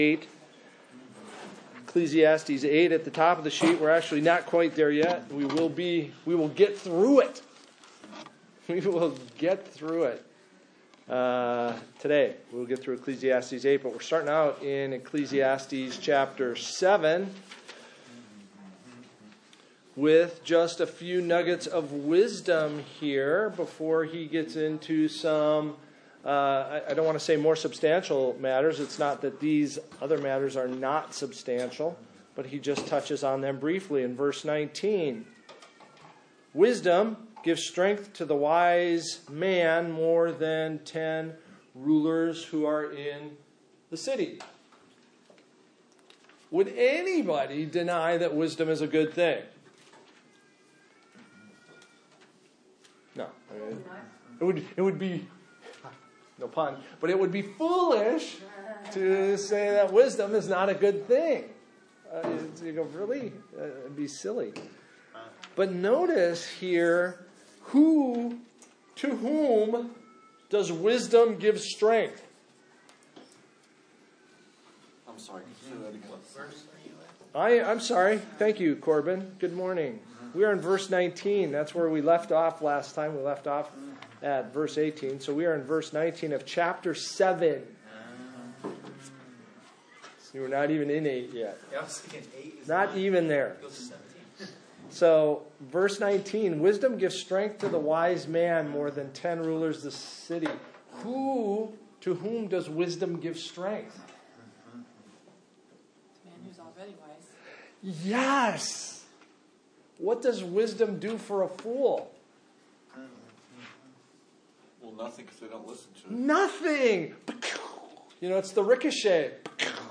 Life Under the Sun Passage: Ecclesiastes 8:1-17 Service Type: Adult Bible Study %todo_render% « Abandoning The Gospel Who Do You Serve?